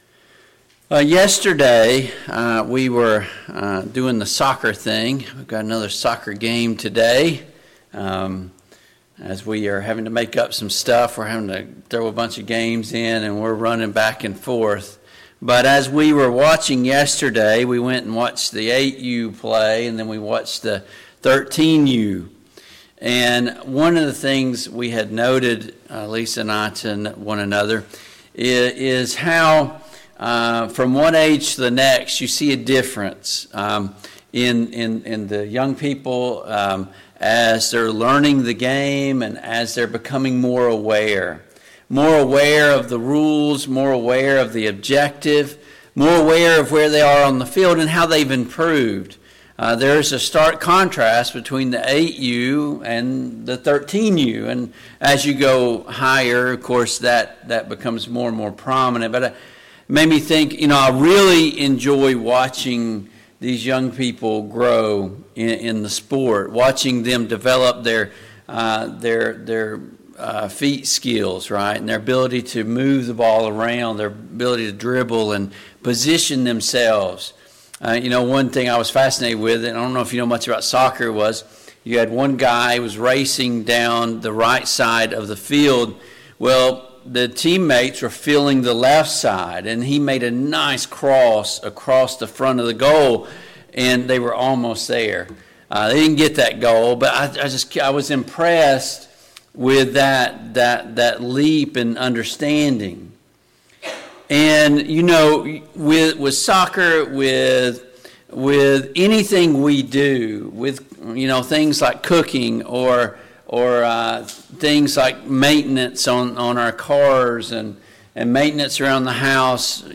Service Type: AM Worship Topics: Teaching in the Home , Teaching our Children , The Family